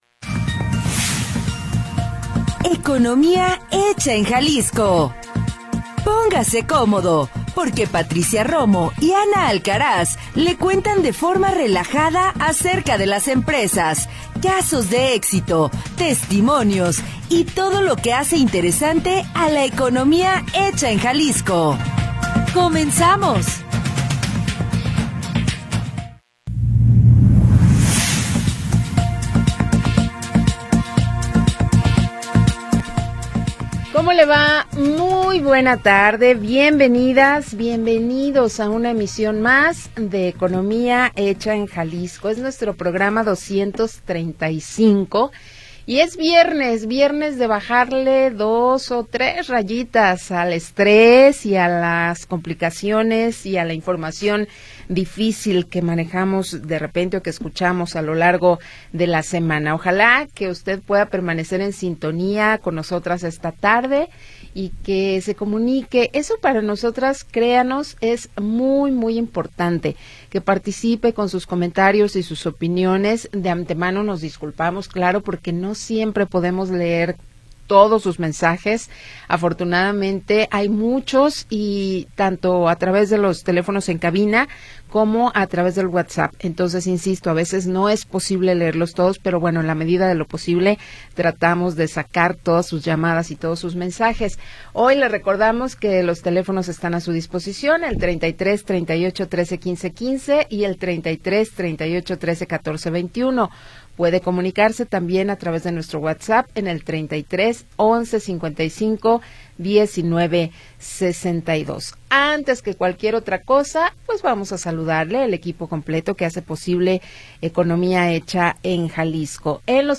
de forma relajada